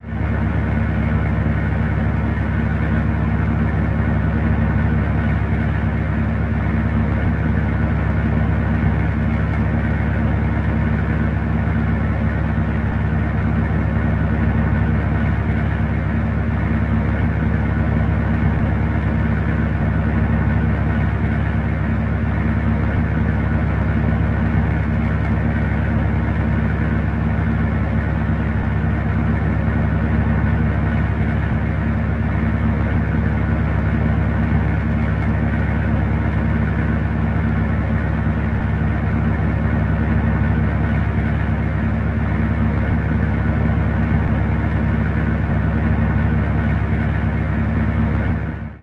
Armored Vehicles
Light armored vehicle idles.